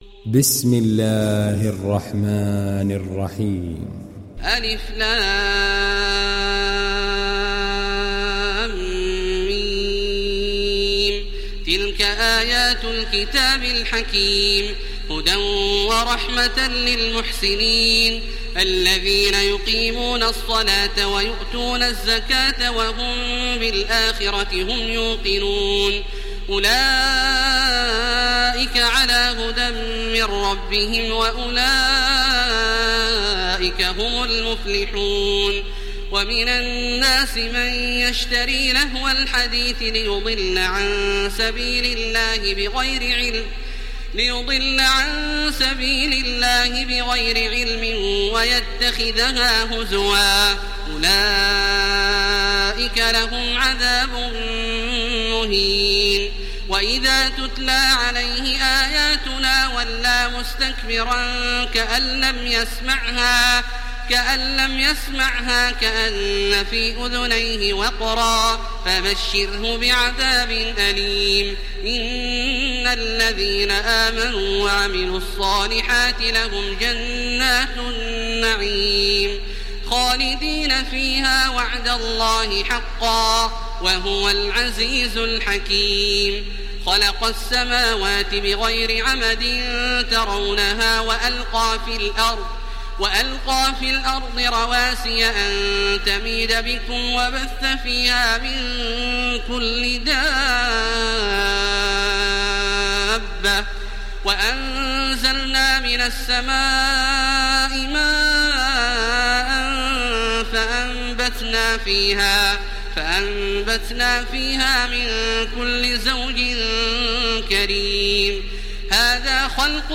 تحميل سورة لقمان تراويح الحرم المكي 1430